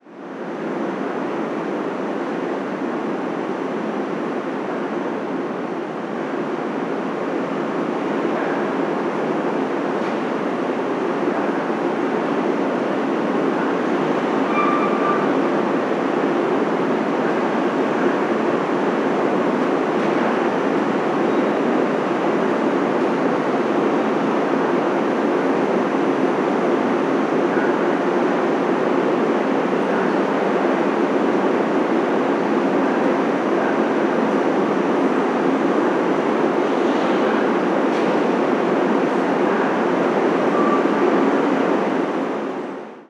Andén del Metro
subterráneo
Sonidos: Transportes
Sonidos: Ciudad